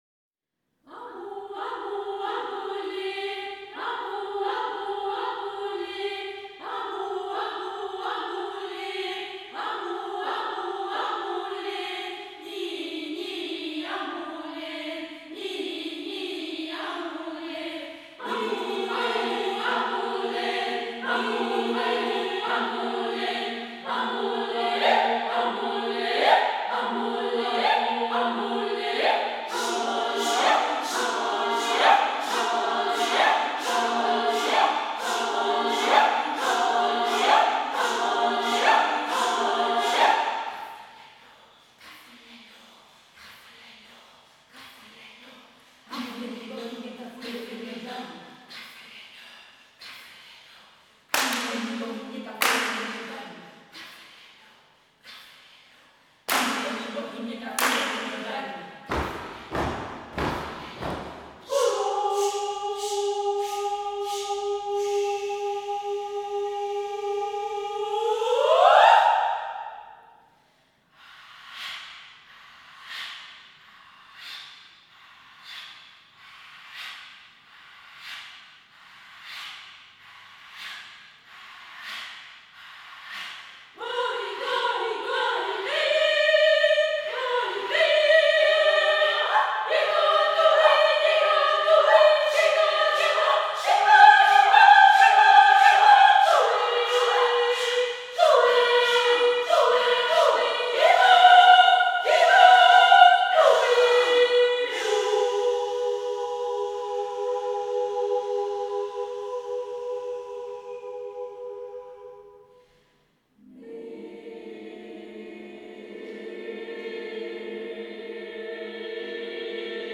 Música vocal
Música tradicional